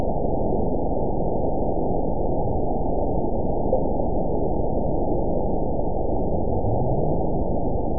event 922054 date 12/26/24 time 01:58:02 GMT (4 months, 2 weeks ago) score 9.43 location TSS-AB02 detected by nrw target species NRW annotations +NRW Spectrogram: Frequency (kHz) vs. Time (s) audio not available .wav